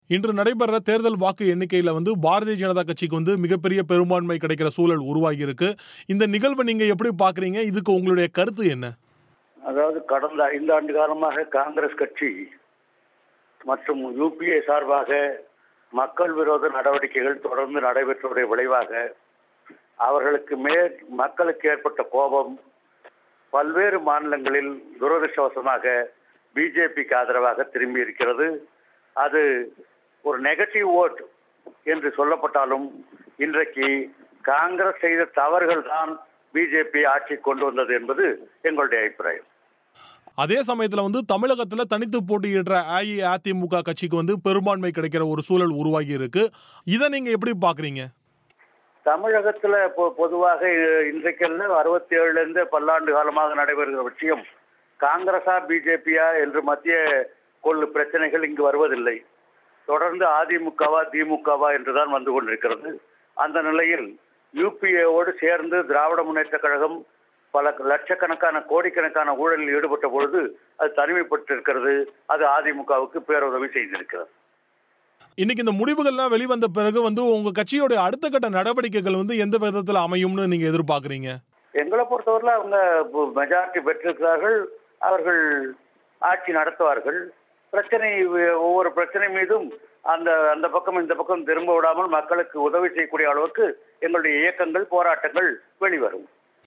பிபிசி தமிழோசைக்கு அளித்த செவ்வி